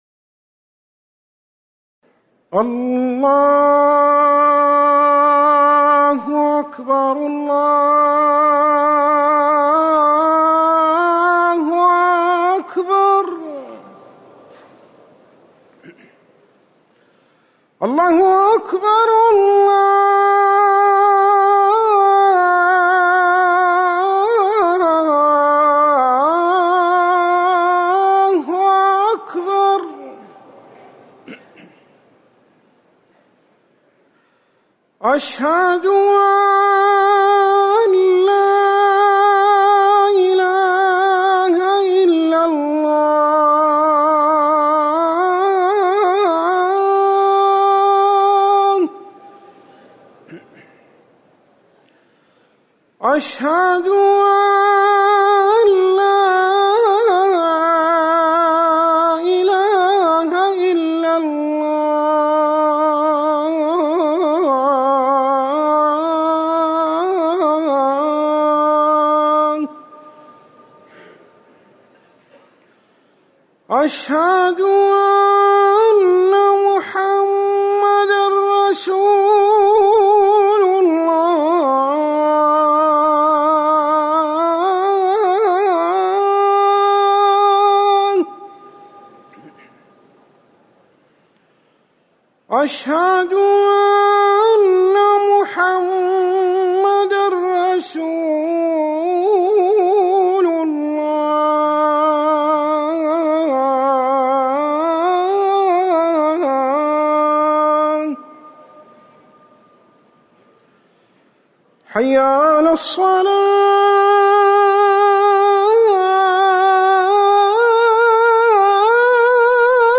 أذان المغرب - الموقع الرسمي لرئاسة الشؤون الدينية بالمسجد النبوي والمسجد الحرام
تاريخ النشر ٩ صفر ١٤٤١ هـ المكان: المسجد النبوي الشيخ